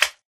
slime2.ogg